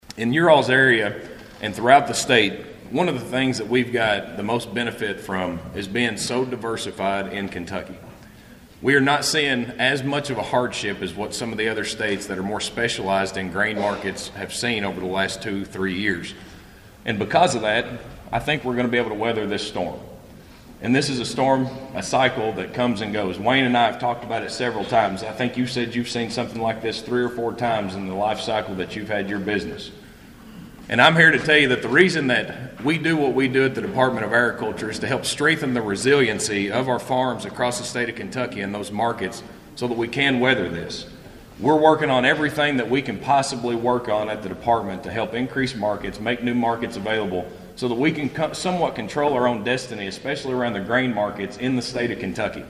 Agriculture Commissioner Shell Keynote Speaker At Christian County Salute To Agriculture Breakfast